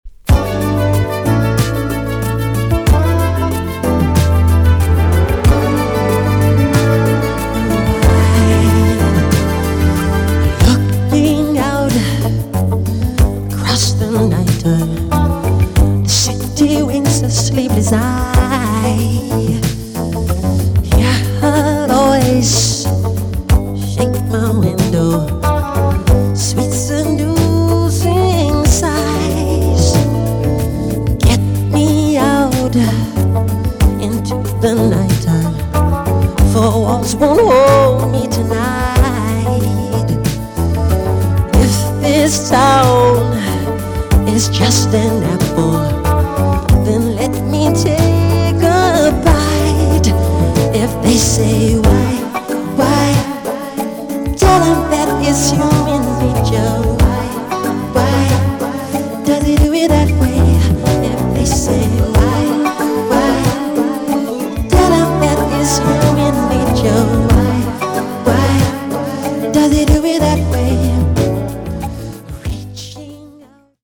EX 音はキレイです。